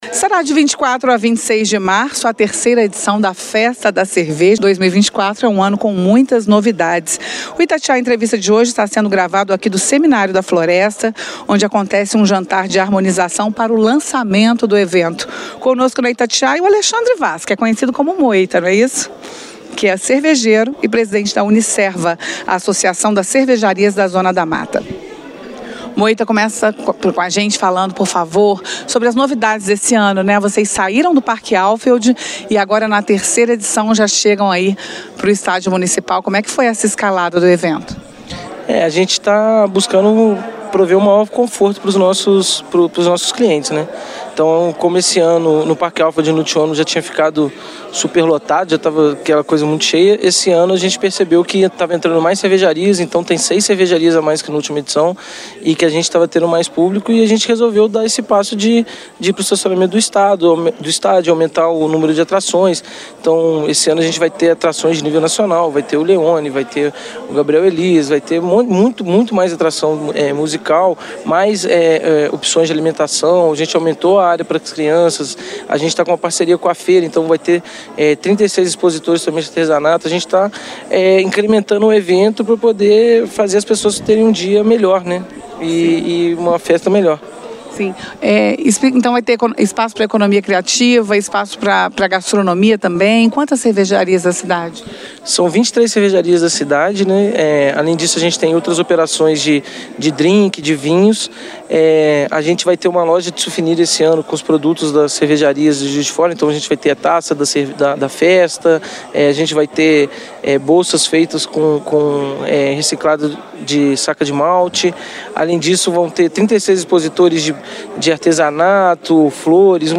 Em um evento na noite de quarta-feira (9) no Seminário da Floresta, representantes dos setores envolvidos falaram sobre a importância da produção cervejeira na cidade, as novidades e a expectativa para este ano.
Itatiaia-Entrevista-Festa-da-Cerveja-2024-Unicerva.mp3